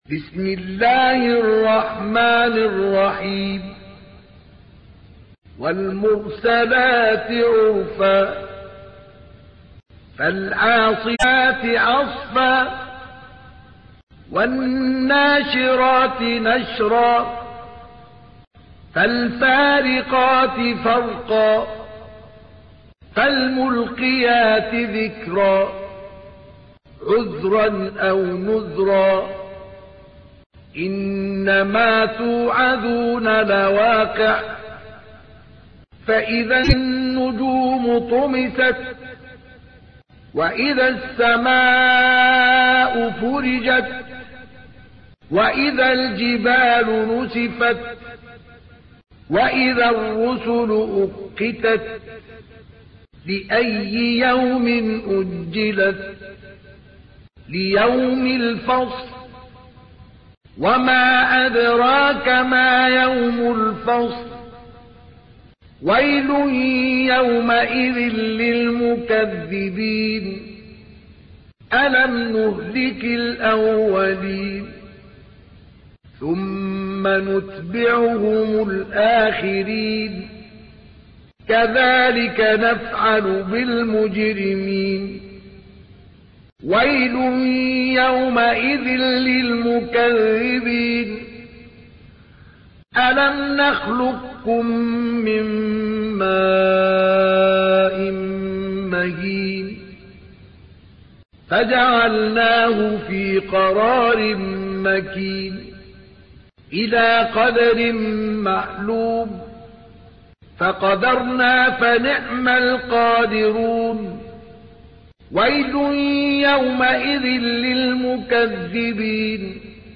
تحميل : 77. سورة المرسلات / القارئ مصطفى اسماعيل / القرآن الكريم / موقع يا حسين